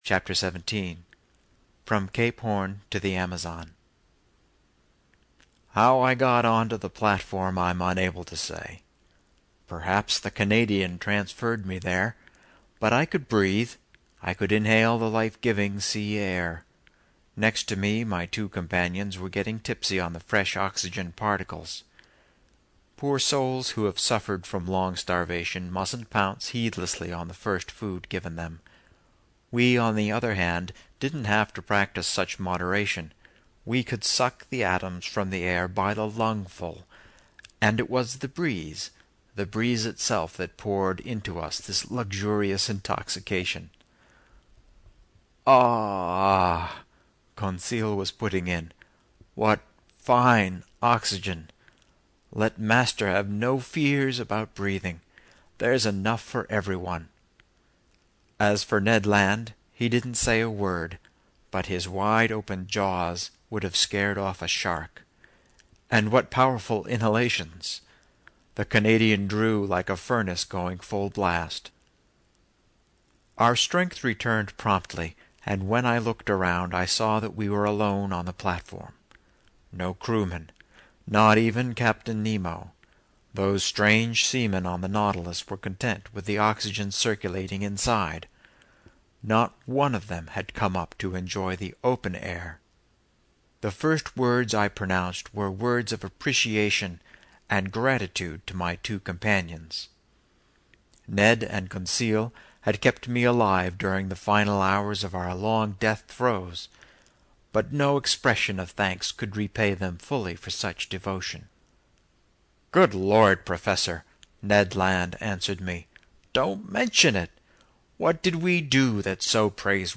英语听书《海底两万里》第478期 第30章 从合恩角到亚马逊河(1) 听力文件下载—在线英语听力室